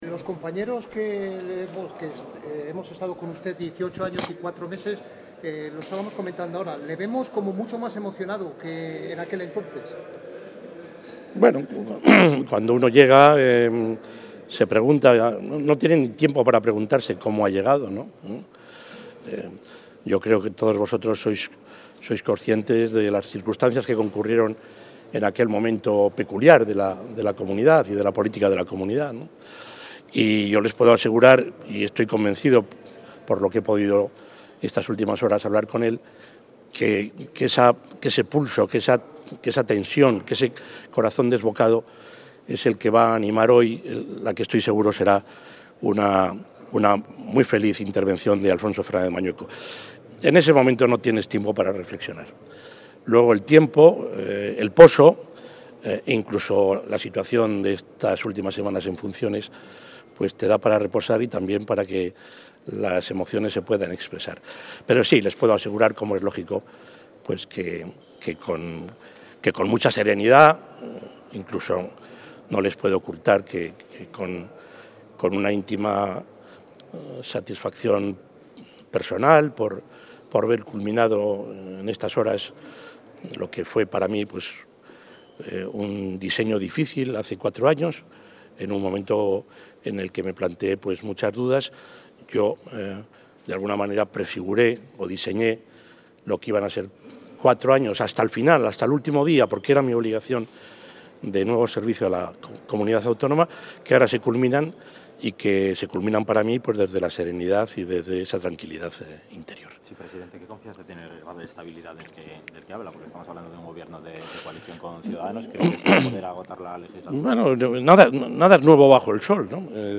Declaraciones del presidente Herrera, previas al debate de investidura del candidato a la Presidencia de la Junta de Castilla y León
Declaraciones del presidente.